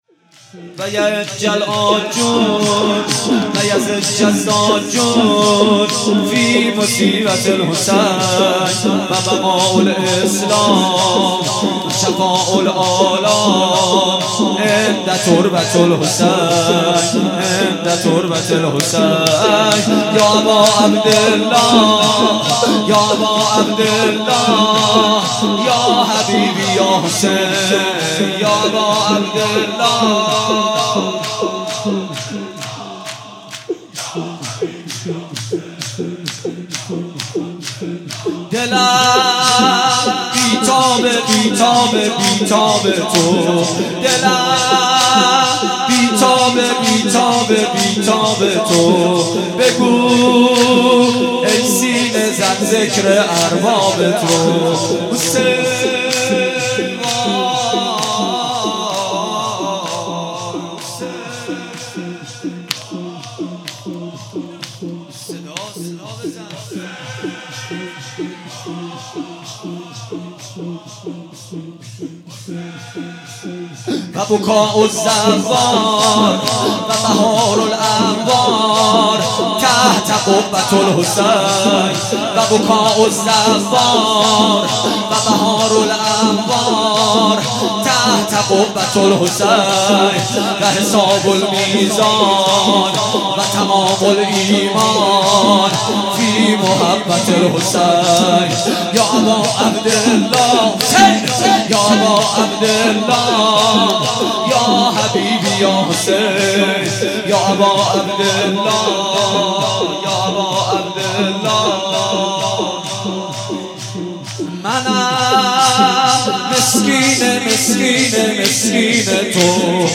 بيست و هفتم صفر 95 - شور - ویعجل العاجون